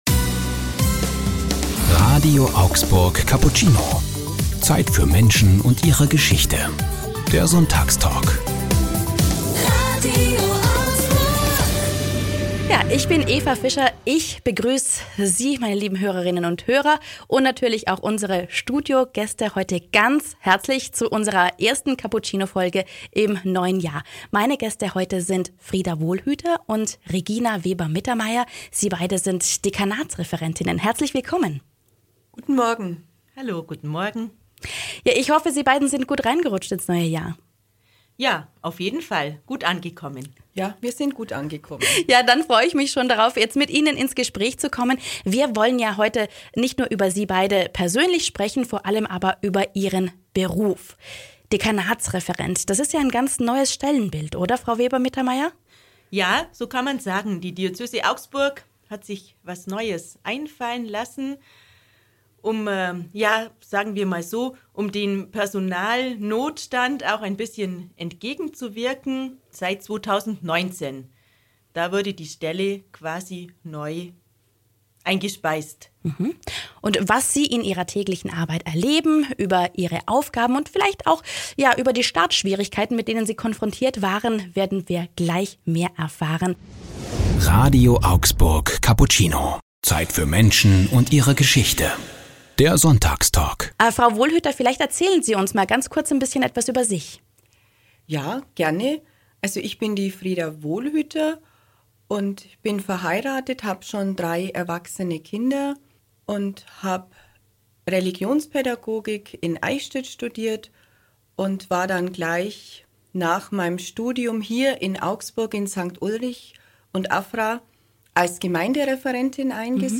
Thema Dekanatsreferenten im Sonntagstalk an Neujahr ~ RADIO AUGSBURG Cappuccino Podcast